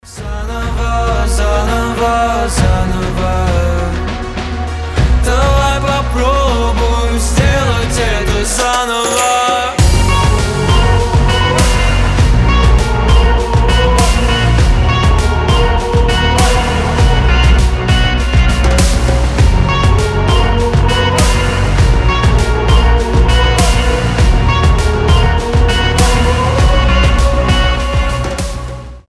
• Качество: 320, Stereo
поп
мужской вокал
future bass